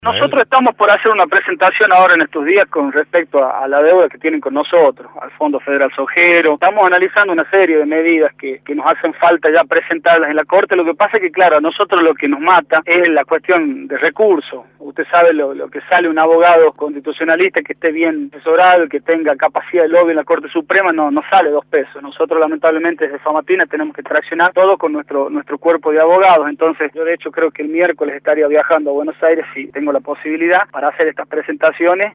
Ismael Bordagaray, intendente de Famatina, por Radio La Red
ismael-bordagaray-intendente-de-famatina-por-radio-la-red.mp3